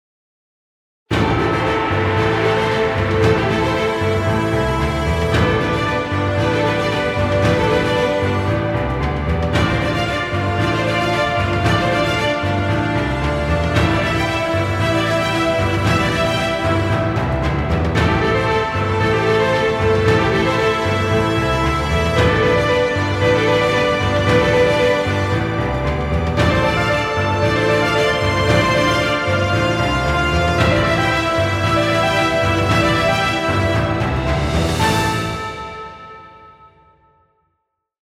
Epic music, exciting intro, or battle scenes.